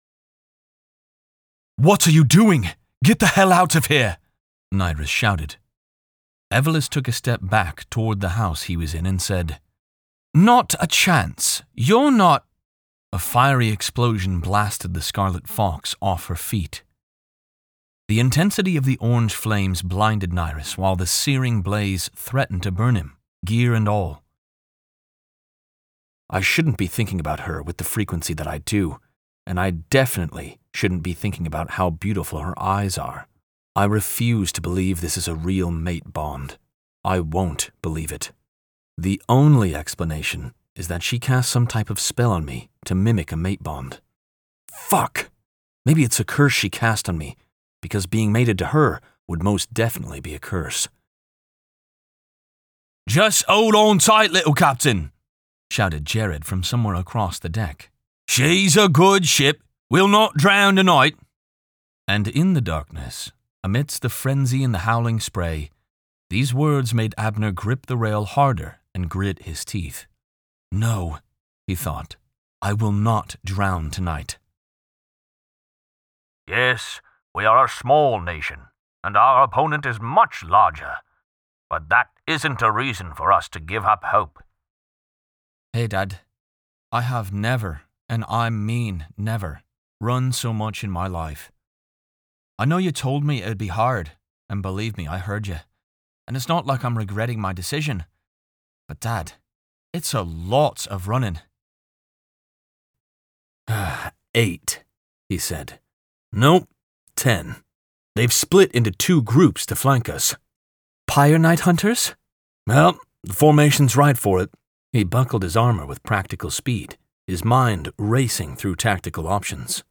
Audiobook Narrator & Voiceover
I strive to create realistic characters and bring a full cast of voices ready for the best listening experience!
A Collection of 30 Second Samples, Various Genres